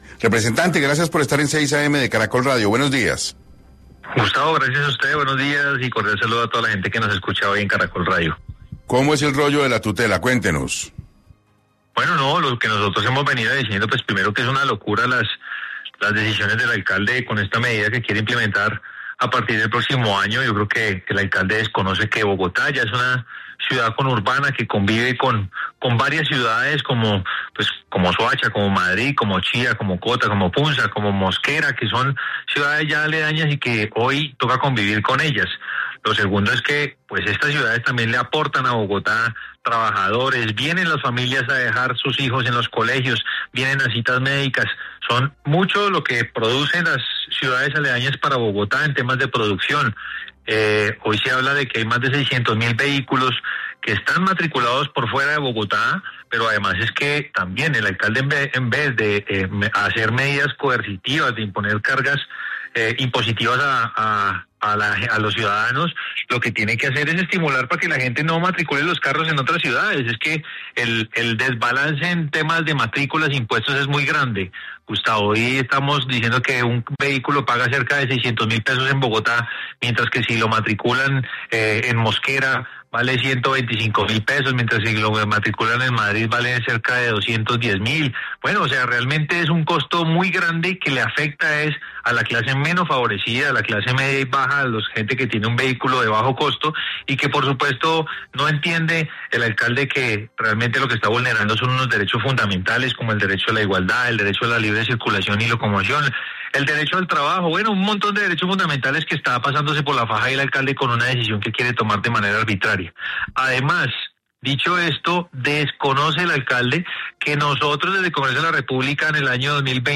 En entrevista con 6AM de Caracol Radio, el representante indicó que: “es una locura las decisiones que ha tomado el alcalde con esta medida que quiere implementar a partir del próximo año. Yo creo que el alcalde desconoce que Bogotá ya es una ciudad urbana que convive con varias ciudades como Soacha, como Madrid, como Chía que son ciudades aledañas y que también le aportan a Bogotá como trabajadores que vienen en familias a dejar sus hijos en los colegios, vienen a citas médicas, son mucho lo que producen las ciudades aledañas para Bogotá en temas de producción”.